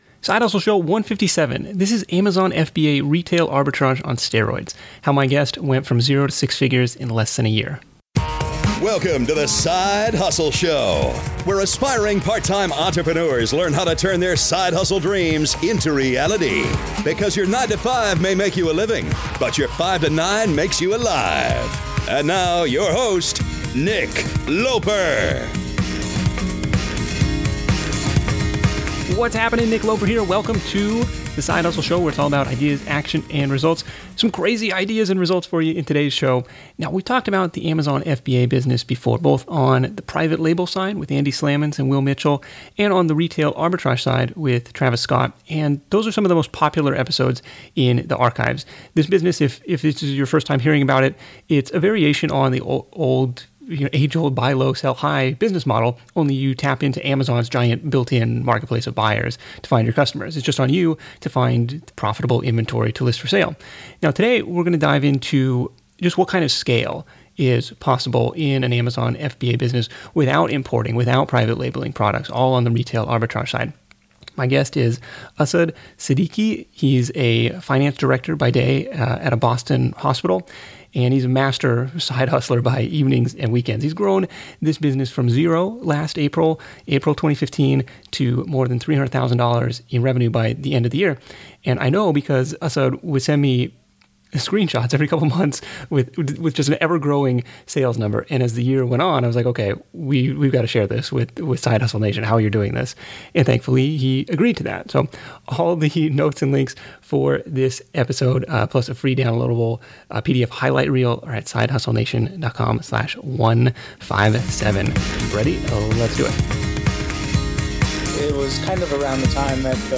Check out our conversation to learn how it happened.